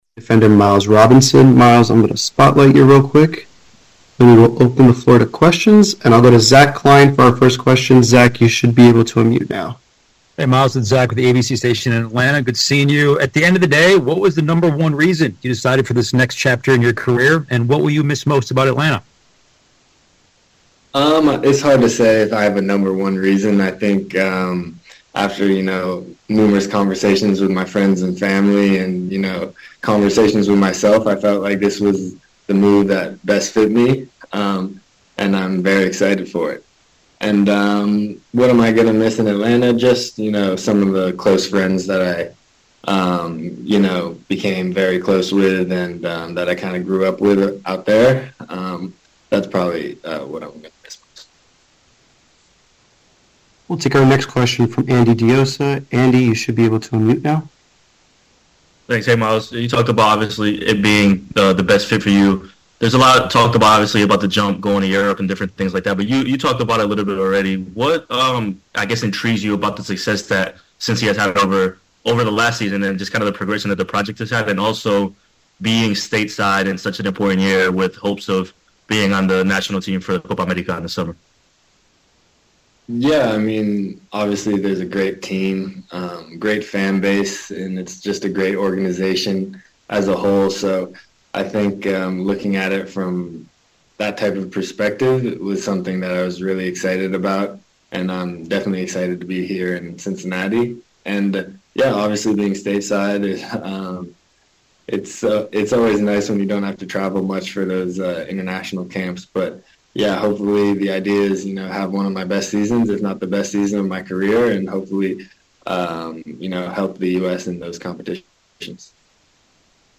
Miles Robinson FC Cincinnati Defender (B) On why he left Atlanta for Cincinnati in free agency, playing on grass more than turf and his new teammates Preseason 2024.mp3